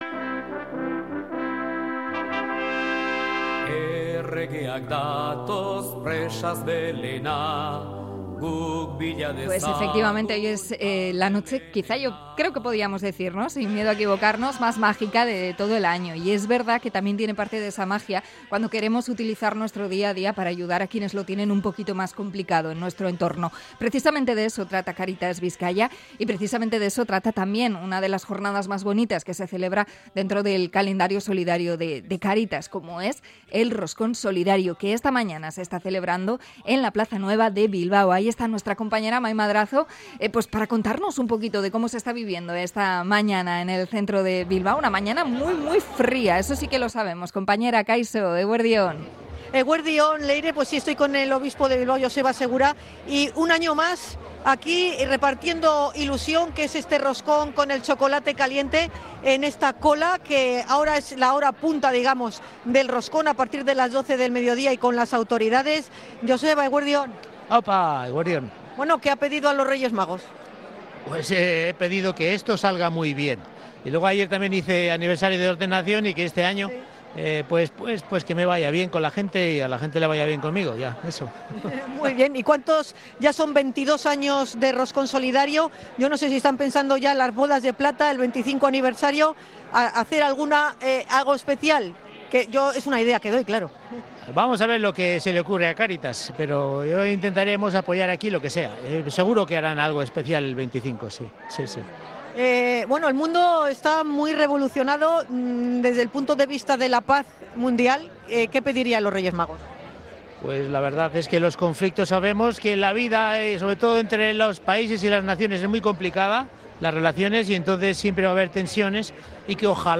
Conexión desde la celebración del Roscón Solidario de Cáritas Bizkaia
Nuestra compañera ha podido charlar unos minuitos con el obispo de Bilbao, Joseba Segura, al que le ha preguntado sobre los deseos de Navidad y la actualidad internacional, tan convulsa estos días.